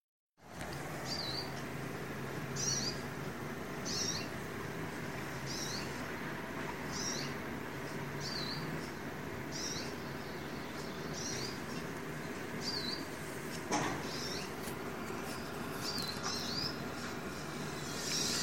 Hooded Siskin (Spinus magellanicus)
Location or protected area: Las Varillas
Condition: Wild
Certainty: Recorded vocal